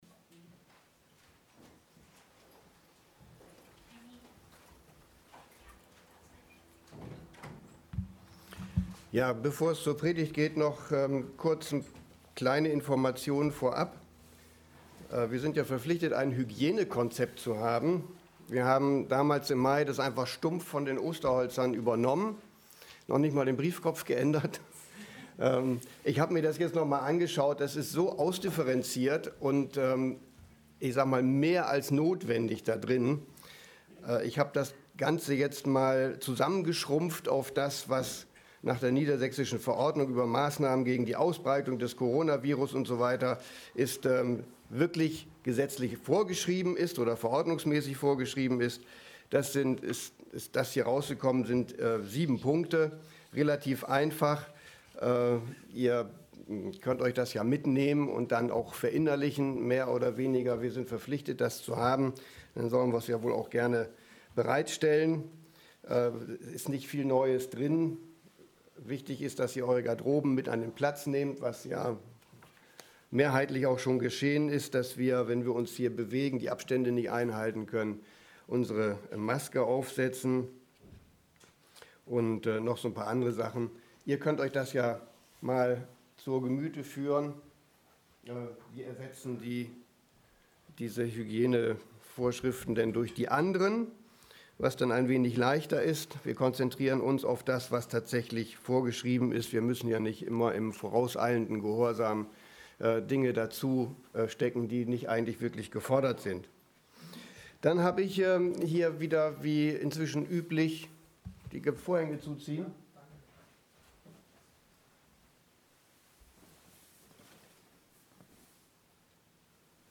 Predigt 08.11.2020